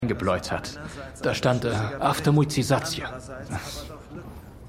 ForAllMankind_5x04_MannAufGangHG.mp3